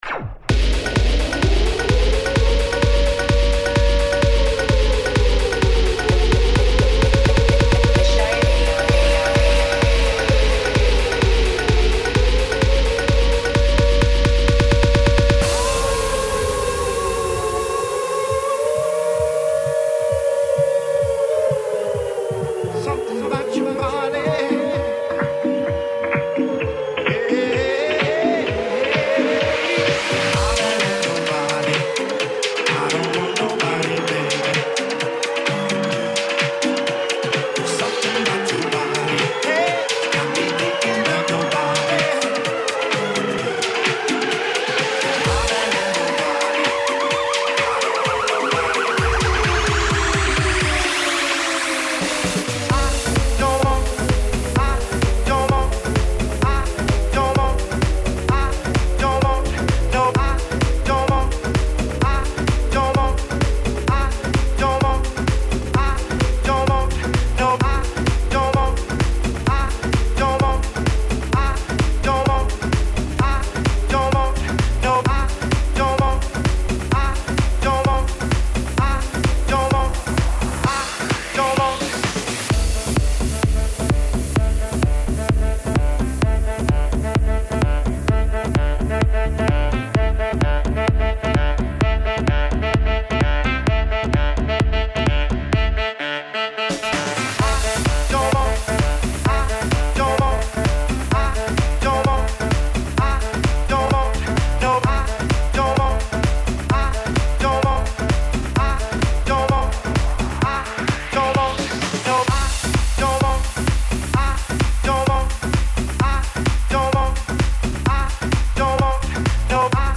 Online House, Tribal, Tech House, Trance and D&B.